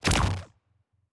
pgs/Assets/Audio/Sci-Fi Sounds/Weapons/Weapon 10 Shoot 2.wav at 7452e70b8c5ad2f7daae623e1a952eb18c9caab4
Weapon 10 Shoot 2.wav